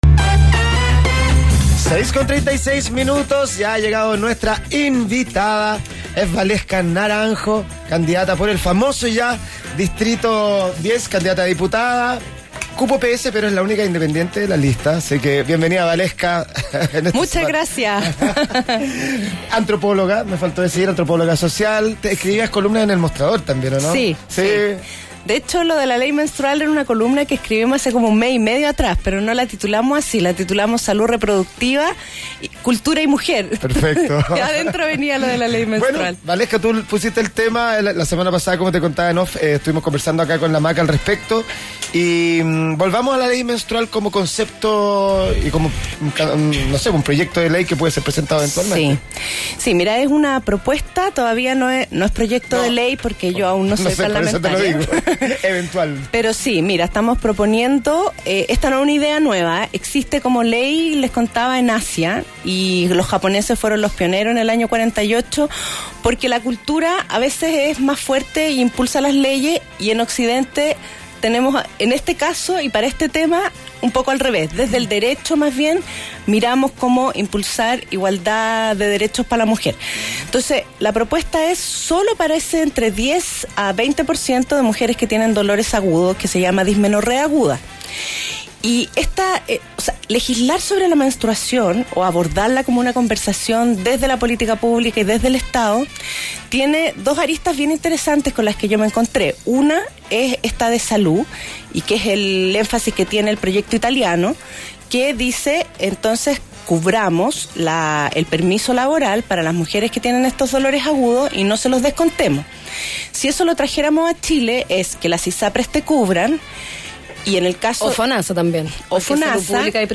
Revisa la entrevista completa y entérate sobre ésta y sus demás propuestas.